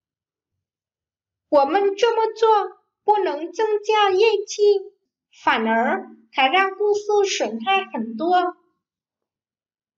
Ủa mân trưa mơ chua pu nấng châng cha giê chi, phản ó hái rang cung xư xuẩn hai khẩn tua.